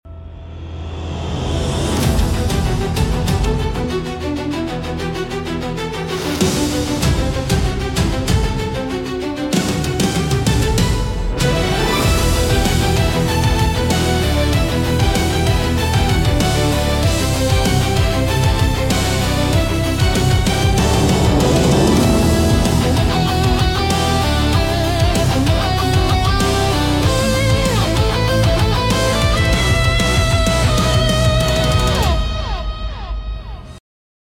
Epic Hybrid